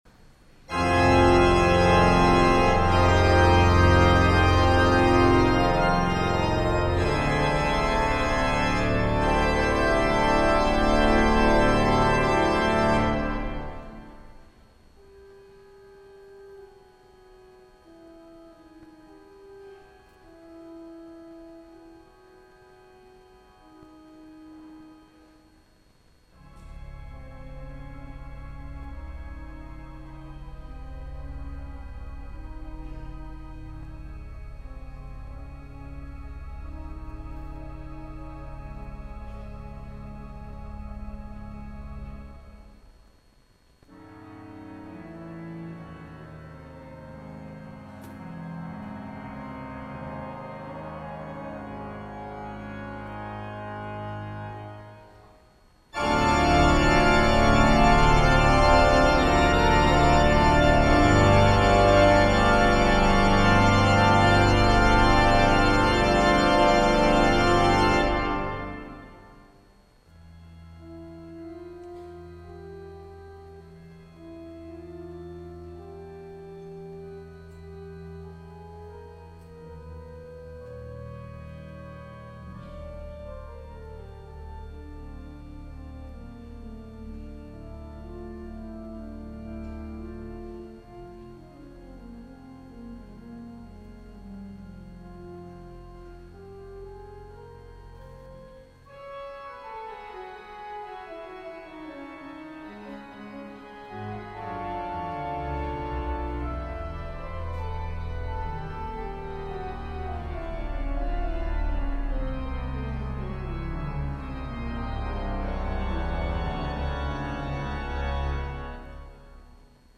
l’orgue